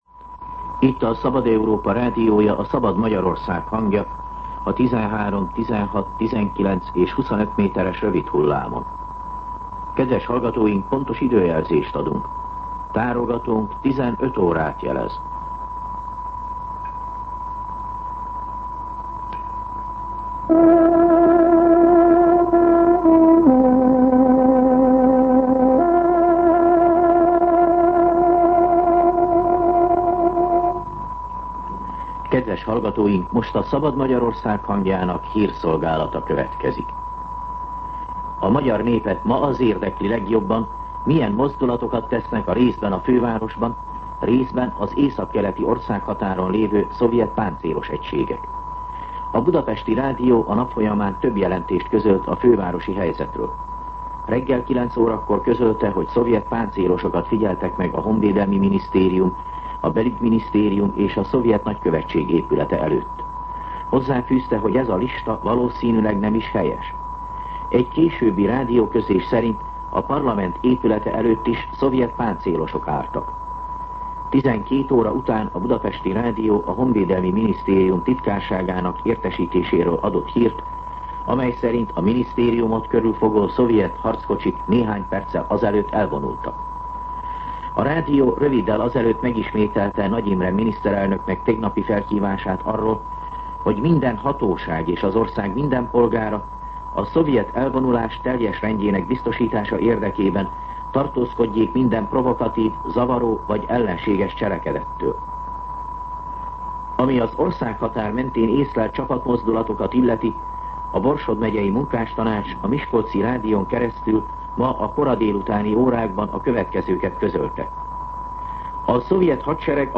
15:00 óra. Hírszolgálat